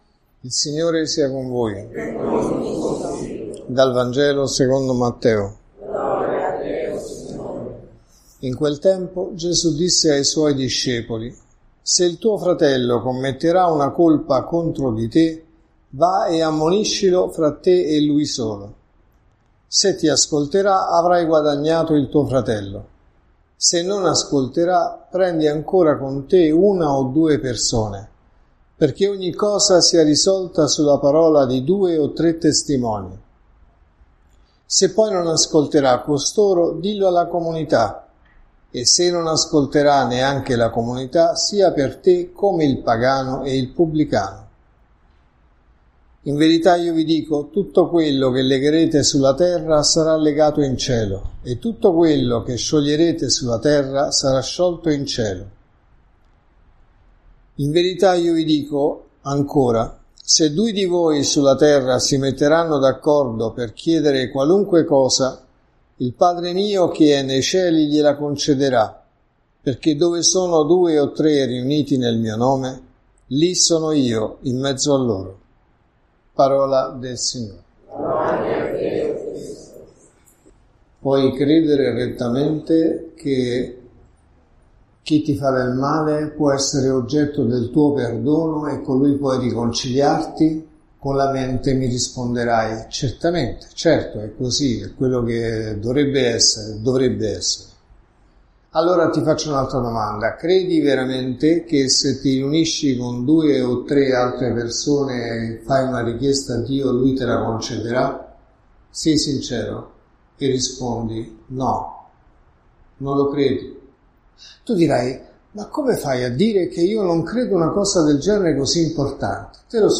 Se ti ascolterà avrai guadagnato il tuo fratello.(Messa del mattino e della sera)